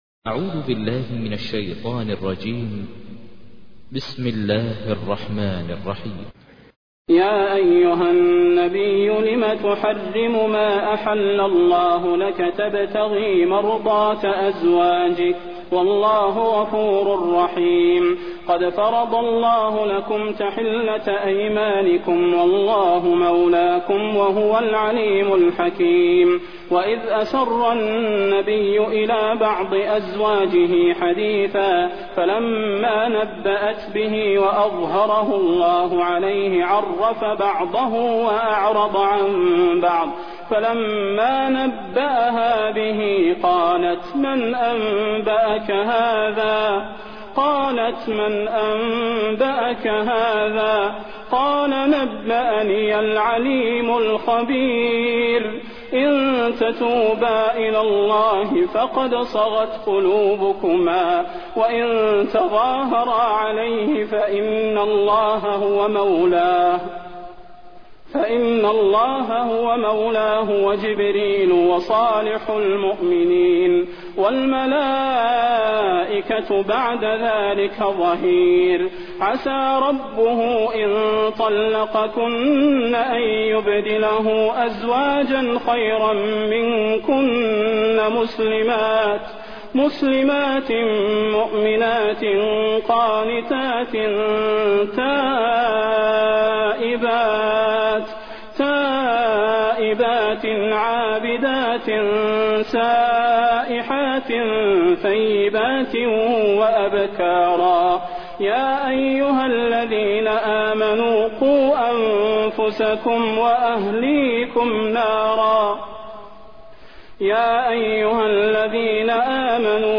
تحميل : 66. سورة التحريم / القارئ ماهر المعيقلي / القرآن الكريم / موقع يا حسين